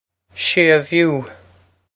séimhiú shay-voo
This is an approximate phonetic pronunciation of the phrase.